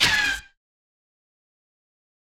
Vox 1 (GAAHH).wav